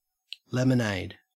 lemonade-au.mp3